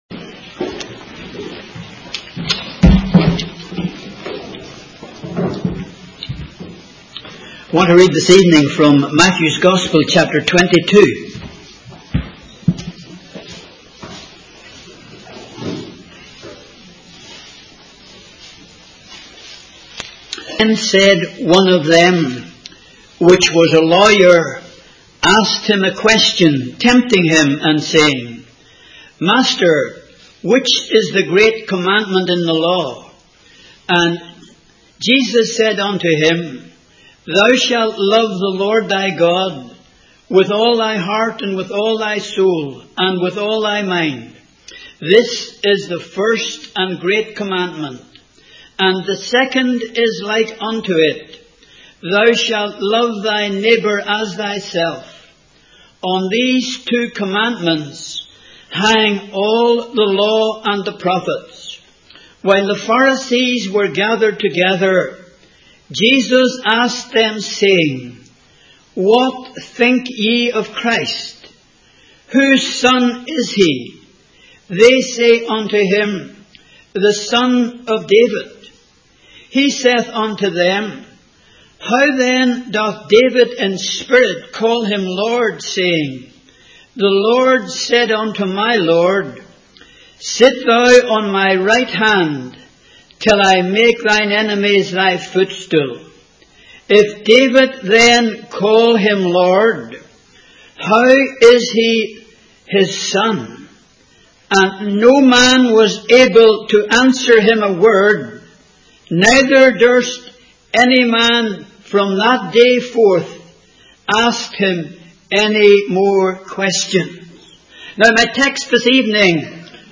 In this sermon, the preacher emphasizes the theme of redemption, which runs throughout the Bible. He highlights the wonderful message of redemption, explaining how sinners can be redeemed through the precious blood of Jesus.